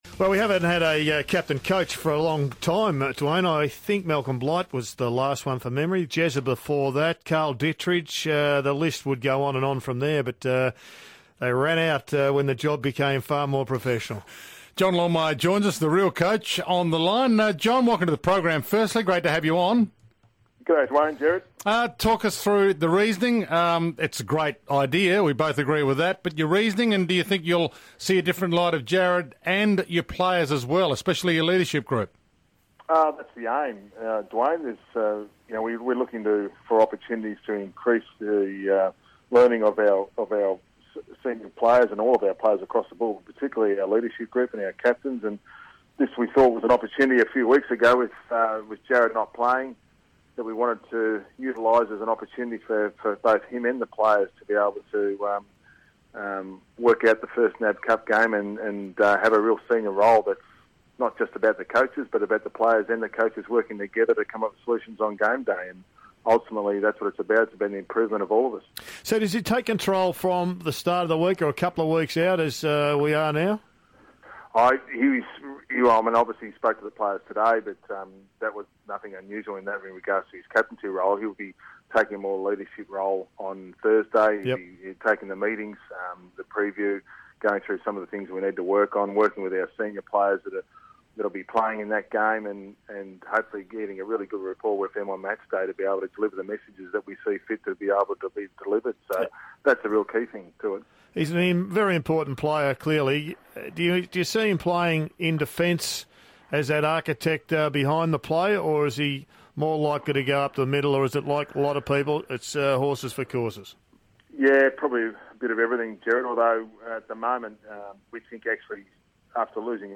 Coach John Longmire speaks to 3AW radio ahead of our first NAB Challenge match against Port Adelaide.